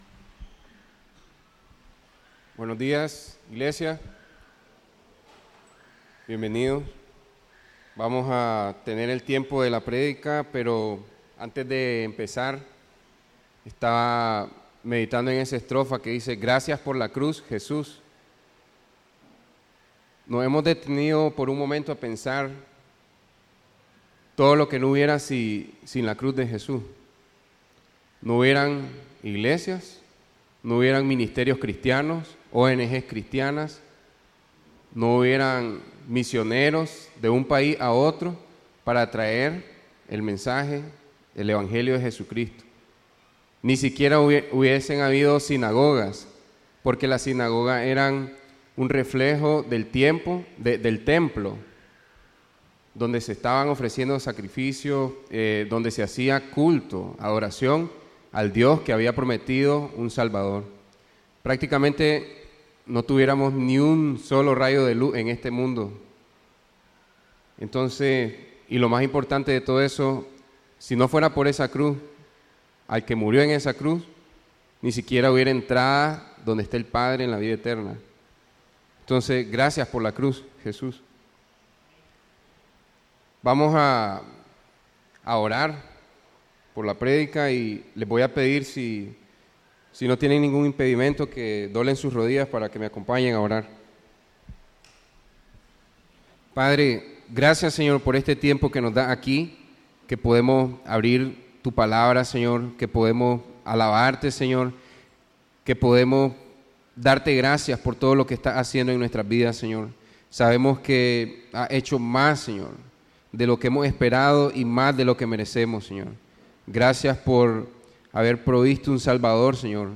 Serie de sermones: Siguiendo los pasos del Maestro Categoria: Vida Cristiana Idioma: es Anterior | Siguiente